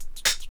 36DR.BREAK.wav